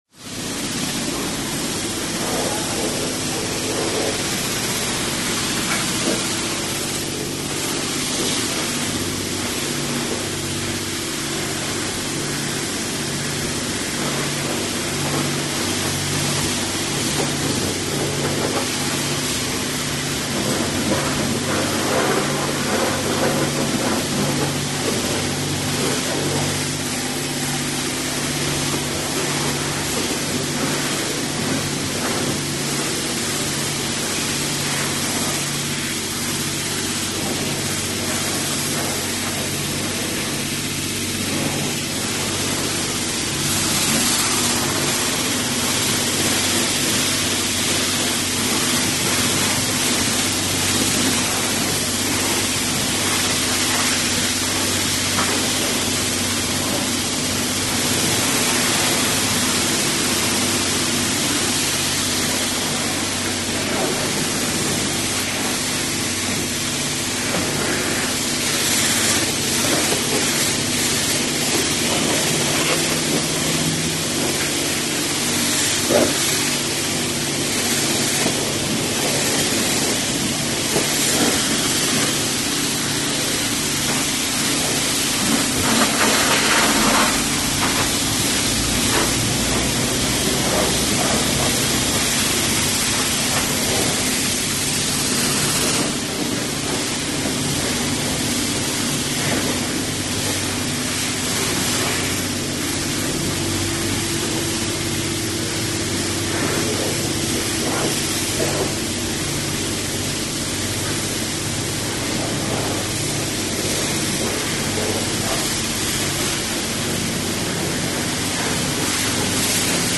Звуки на автомойке самообслуживания